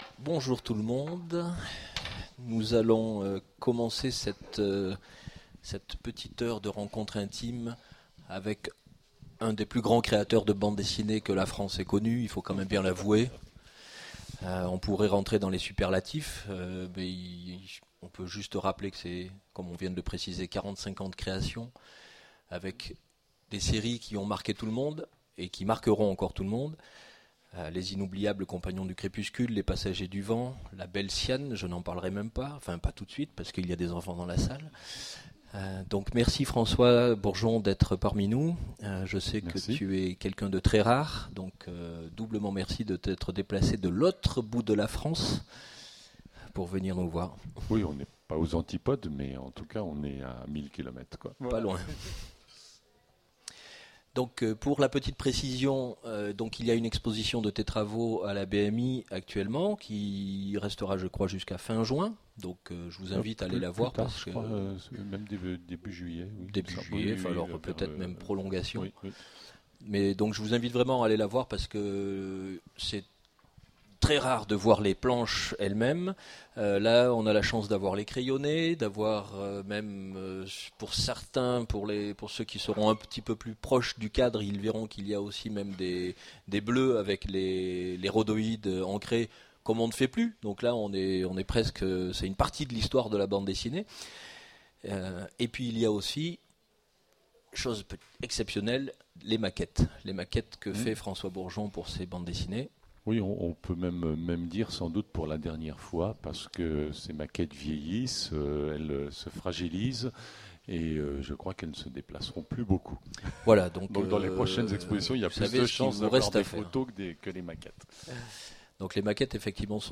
Imaginales 2015 : Conférence François Bourgeon